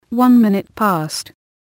こちらは つづりを入力すると、その通りに話してくれるページです。
そこでAPR9600のAnalogInputにPCのLINEOUTを接続したところ、 適度な音量で再生ができるレベルになりました。